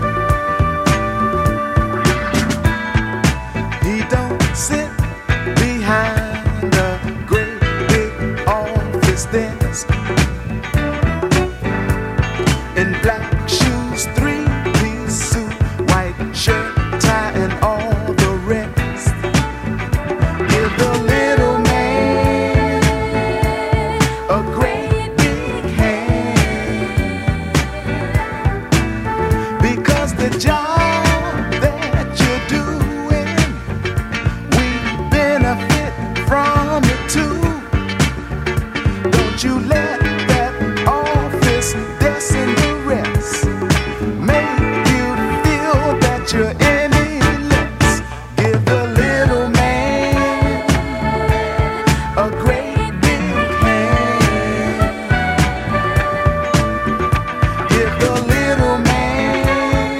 ジャンル(スタイル) SOUL / FUNK